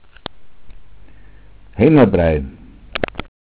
Lateinische Sprachrelikte im bayerischen Dialekt, Flurnamen
Mundart: heimer_brei